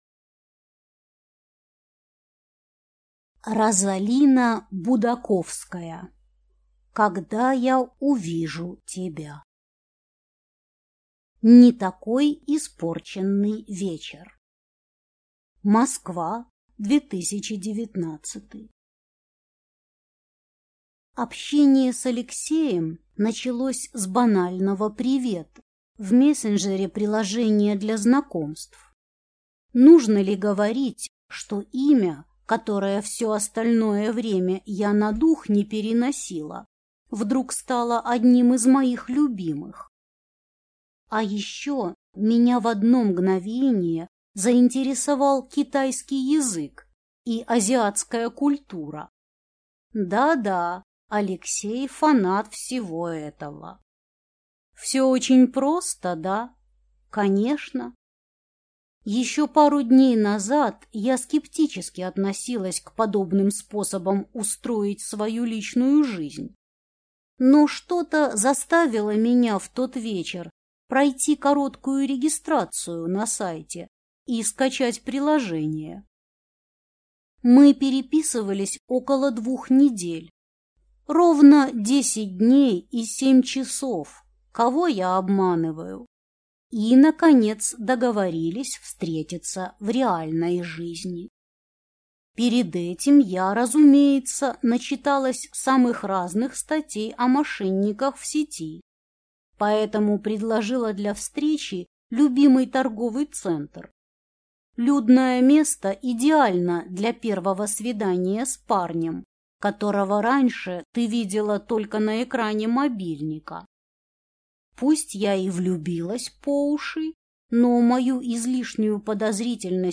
Аудиокнига Когда я увижу тебя…